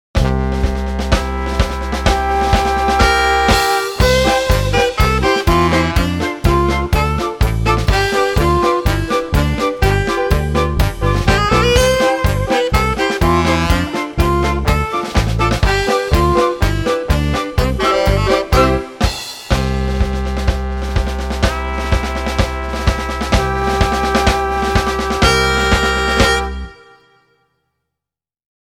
circusmuziek
circusmuziek.mp3